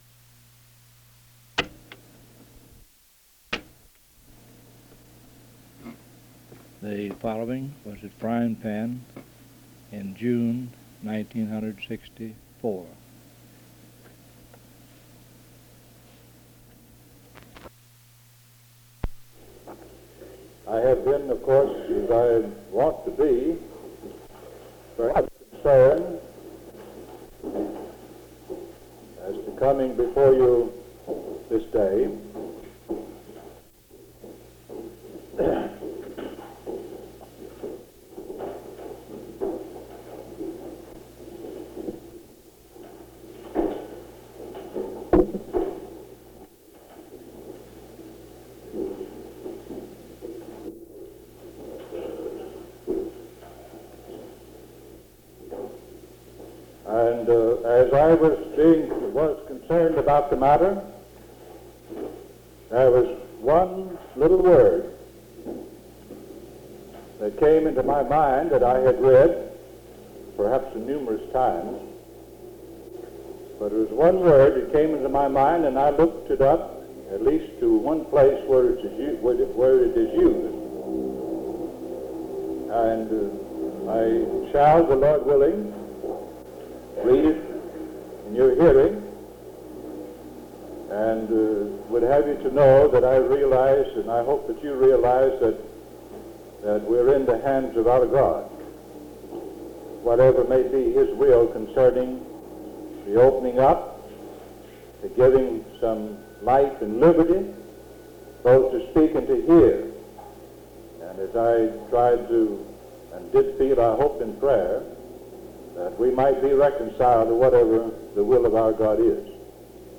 Herndon (Va.)